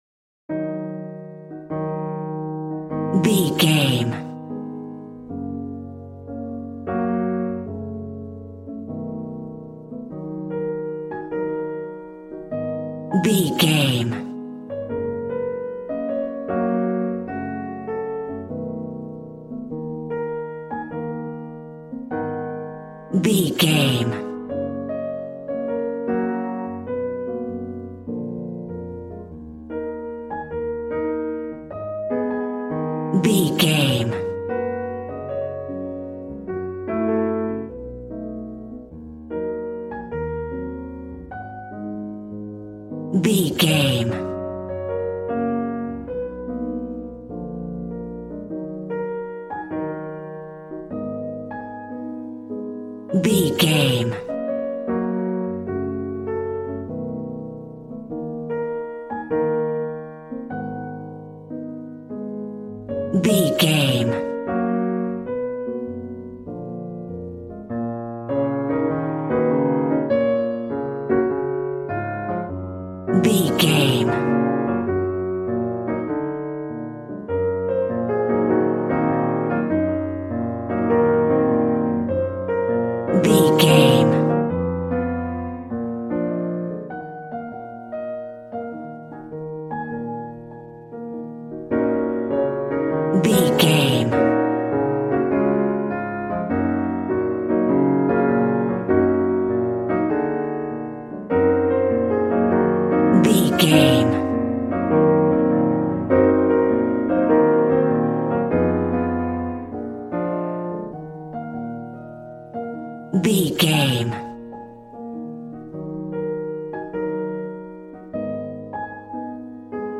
Smooth jazz piano mixed with jazz bass and cool jazz drums.,
Aeolian/Minor
E♭
piano
drums